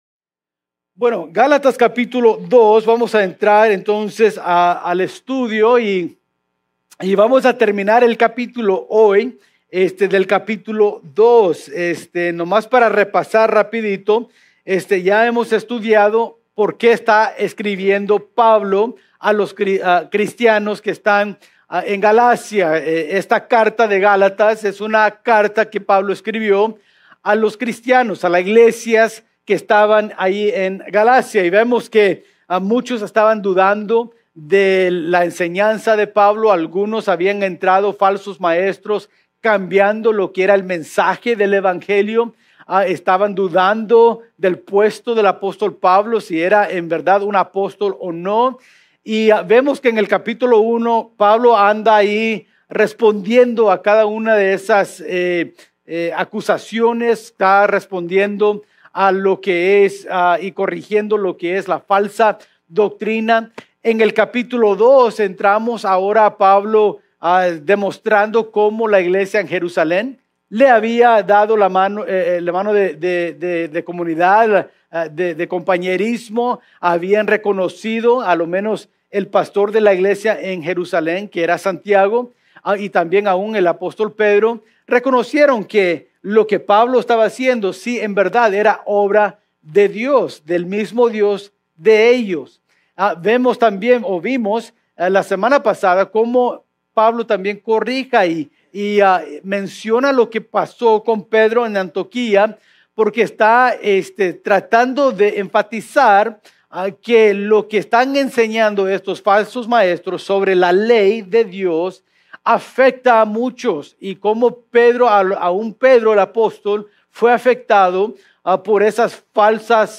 Mensaje del Domingo 3 de Noviembre de 2024 por la mañana.